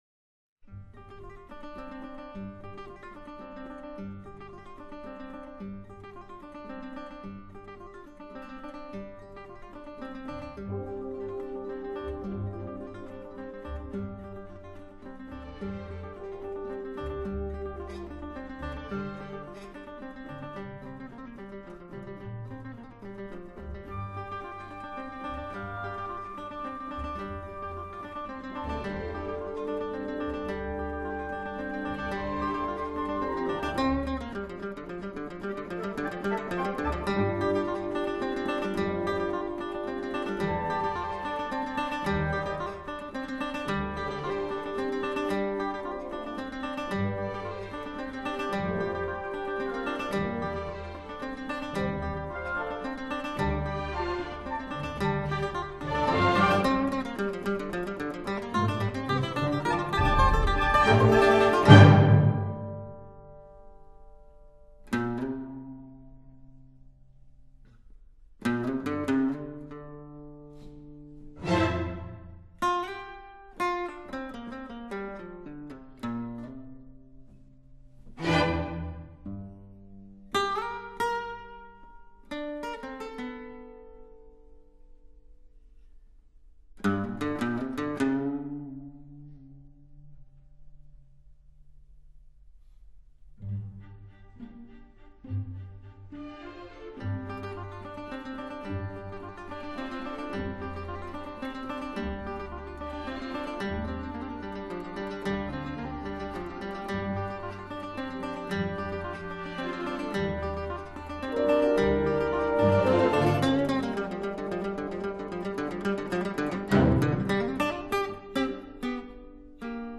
中国弹拨乐
中阮协奏曲